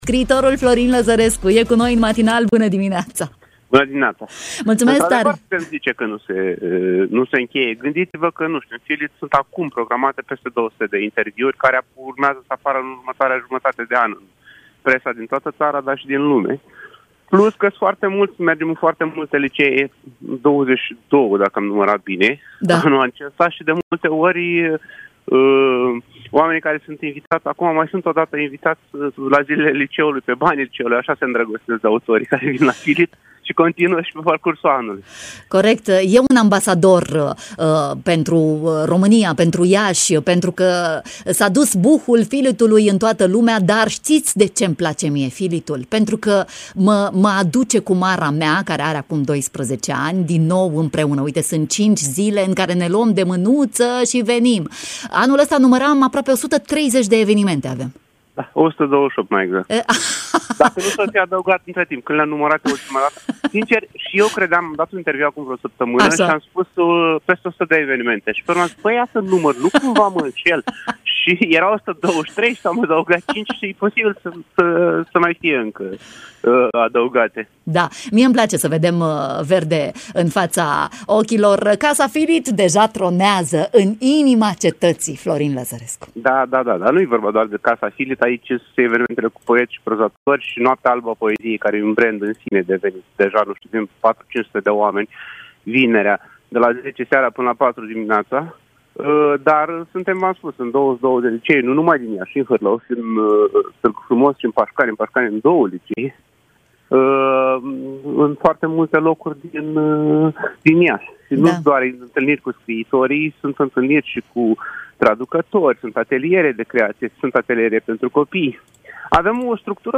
Scriitorul Florin Lăzărescu în direct la Radio Iași
Scriitorul Florin Lăzărescu, directorul festivalului, ne-a adus energia verde FILIT în matinal: